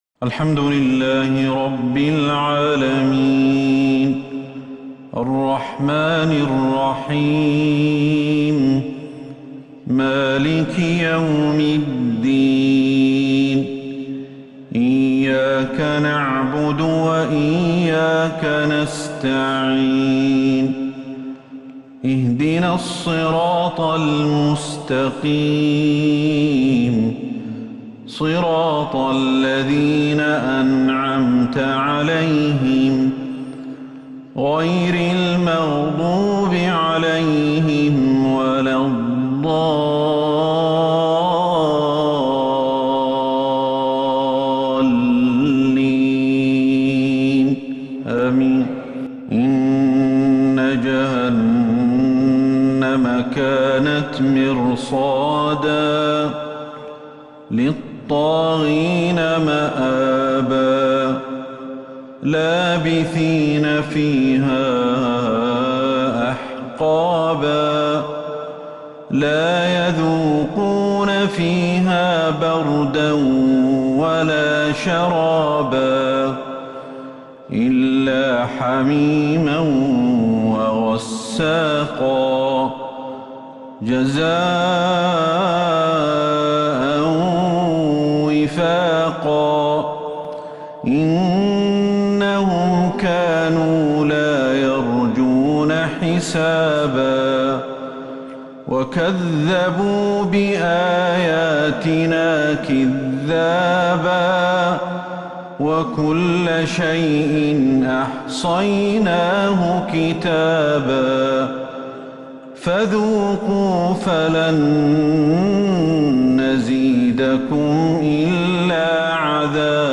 مغرب الأحد 16 ربيع الثاني 1443 هـ آواخر سورتي {النبأ}{النازعات} > 1443 هـ > الفروض - تلاوات الشيخ أحمد الحذيفي